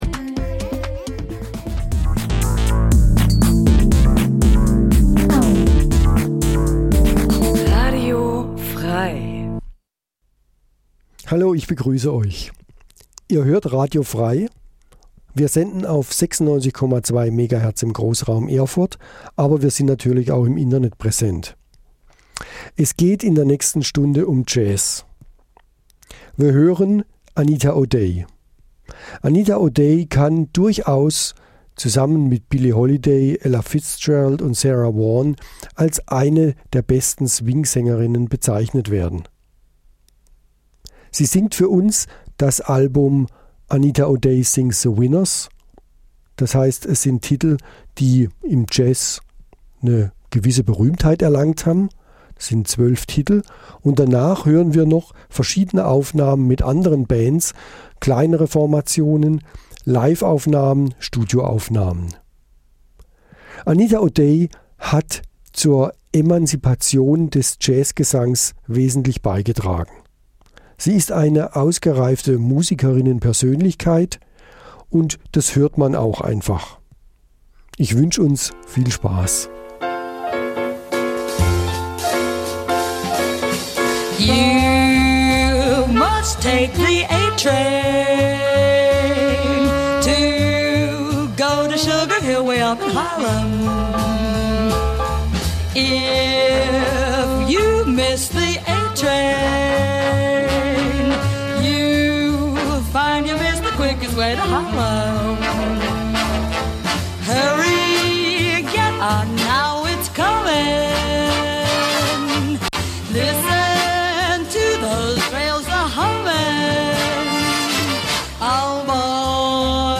Eine Stunde Jazz